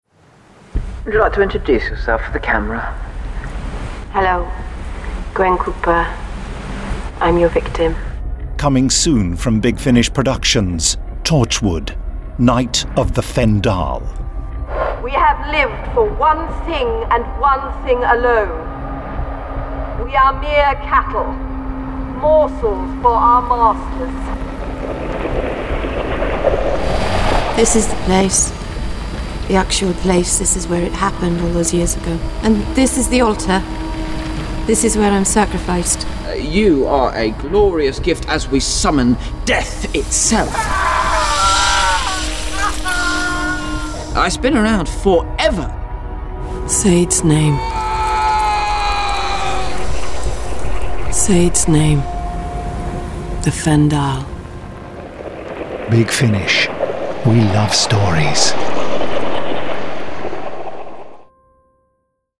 Award-winning, full-cast original audio dramas from the worlds of Doctor Who, Torchwood, Blake's 7, Class, Dark Shadows, The Avengers, Survivors, The Omega Factor, Star Cops, Sherlock Holmes, Dorian Gray, Pathfinder Legends, The Prisoner, Adam Adamant Lives, Space 1999, Timeslip, Terrahawks, Space Precinct, Thunderbirds, Stingray, Robin Hood, Dark Season, UFO, Stargate
Torchwood: Night of the Fendahl Available March 2019 Written by Tim Foley Starring Eve Myles This release contains adult material and may not be suitable for younger listeners. From US $10.04 Download US $10.04 Buy Save money with a bundle Login to wishlist 34 Listeners recommend this Share Tweet Listen to the trailer Download the trailer